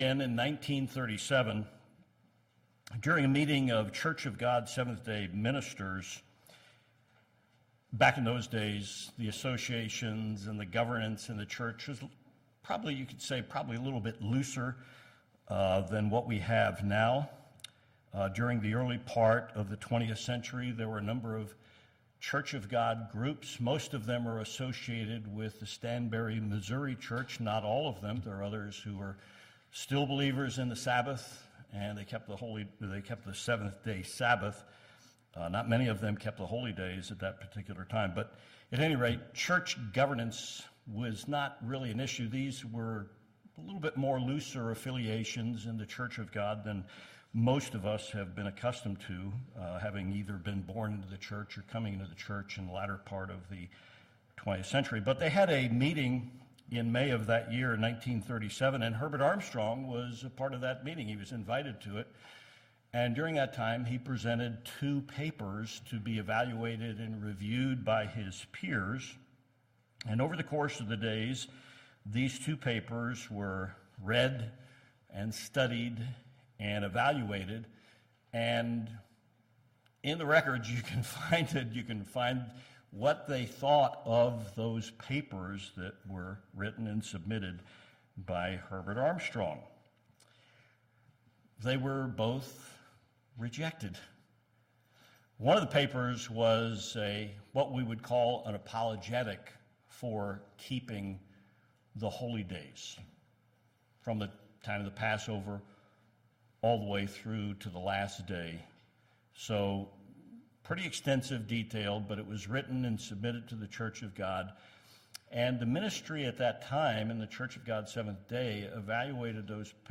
Two important prophetic concepts were rejected by Church of God ministers in 1937. When prophecy is rejected by God's people, history shows it doesn't end well for them. This sermon challenges the listener to understand vital truths of Bible prophecy.